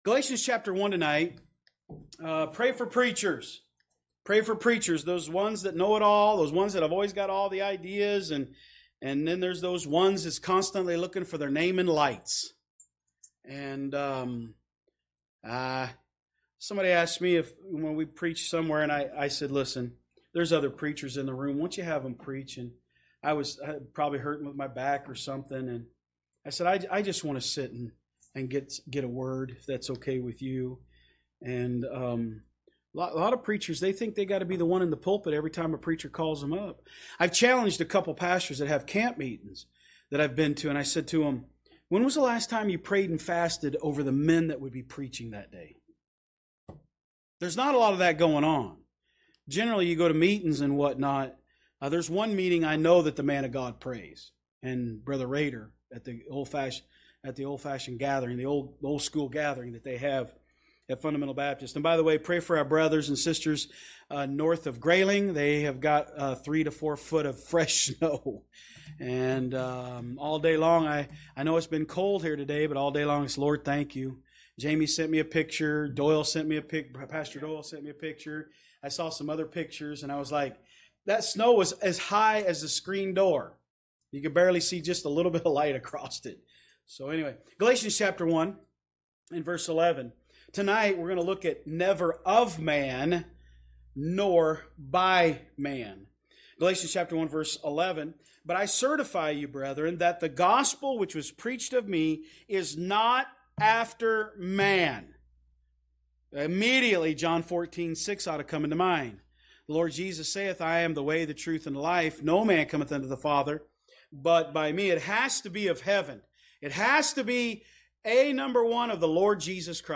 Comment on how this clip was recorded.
From Series: "Wednesday Service"